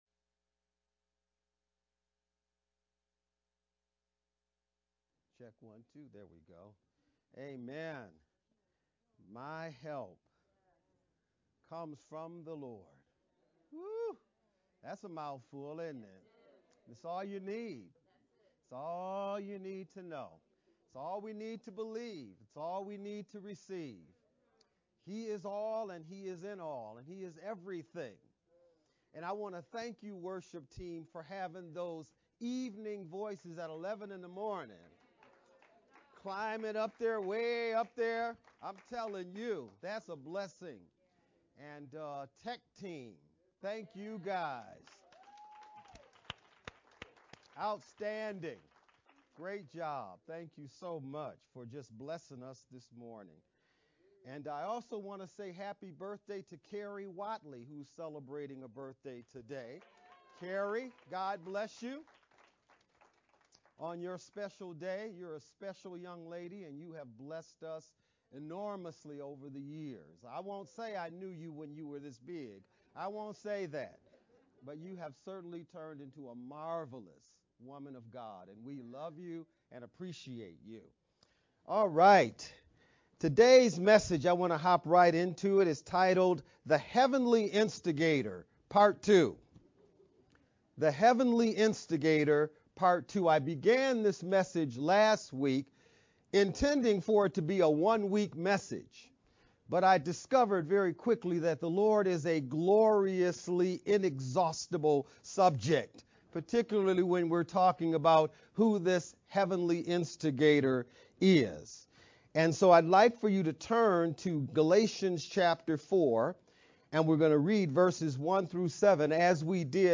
VBCC-Sermon-11-13-edited-sermon-only-Mp3-CD.mp3